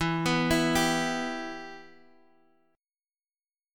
Em chord